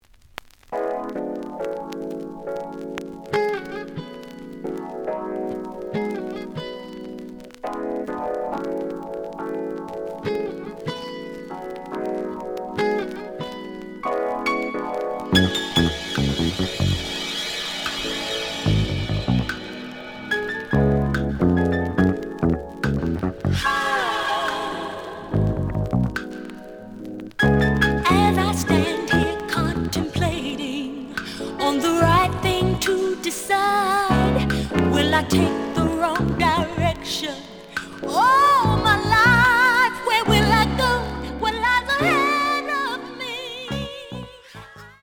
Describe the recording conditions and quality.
The audio sample is recorded from the actual item. Slight noise on B side.)